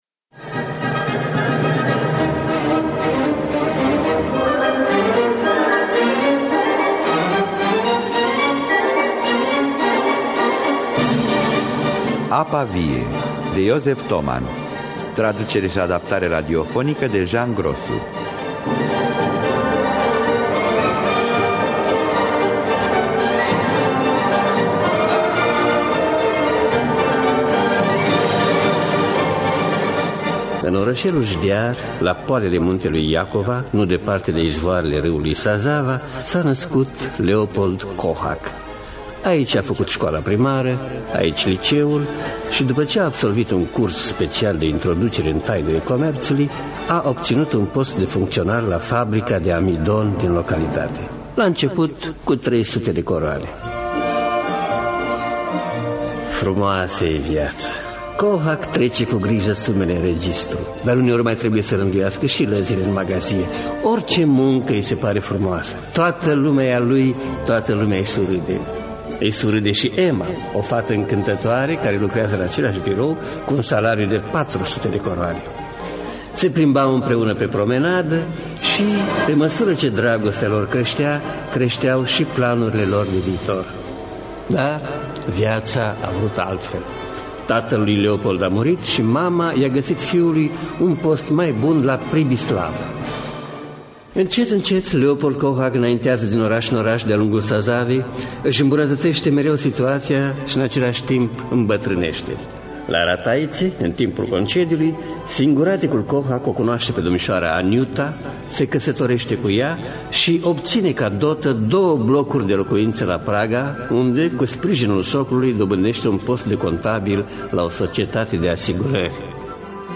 Josef Toman – Apa Vie (1982) – Teatru Radiofonic Online